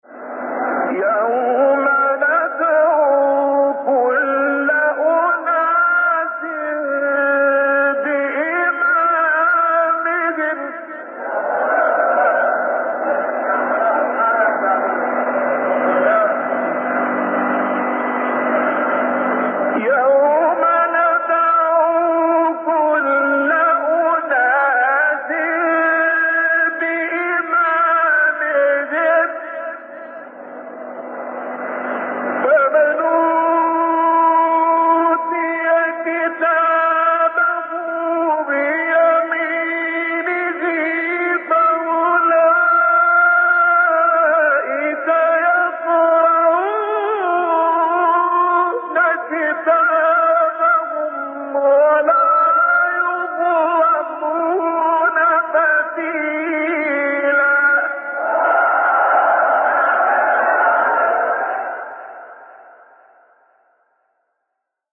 آیه 71 سوره اسرا استاد شعبان صیاد | نغمات قرآن | دانلود تلاوت قرآن